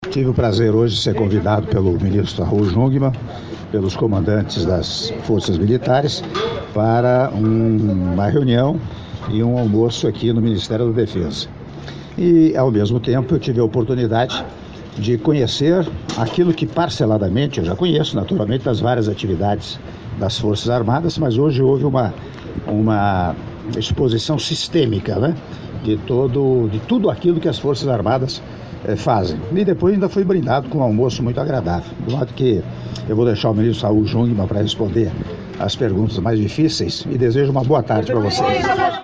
Áudio da entrevista coletiva concedida pelo Presidente da República, Michel Temer, após Reunião do Conselho Militar de Defesa - Brasília/DF- (39s)